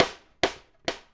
Sidestick 01.wav